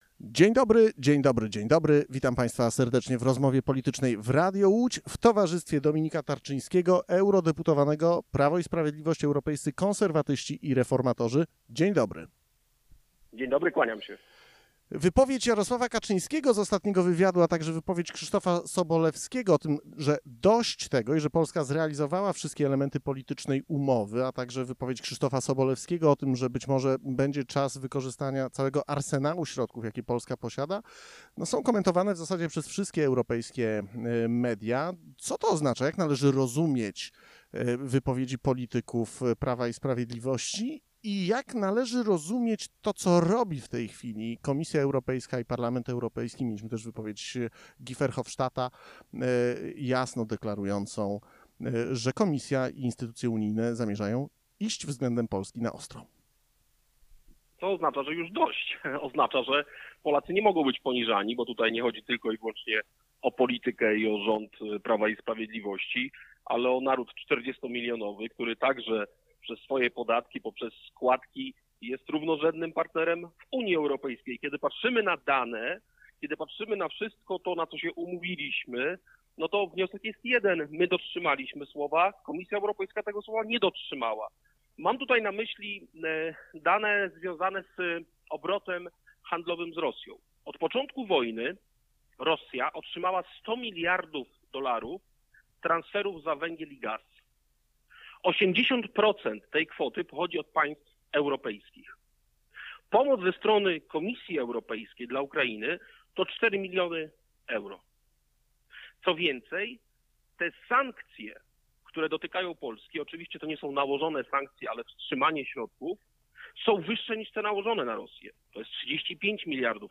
Gościem po 8 w Radiu Łódź był europoseł Prawa i Sprawiedliwości Dominik Tarczyński. Rozmawialiśmy o konflikcie między Komisją Europejską, a polskim rządem w sprawie zmian w sądownictwie i wypłaty pieniędzy z Krajowego Planu Odbudowy.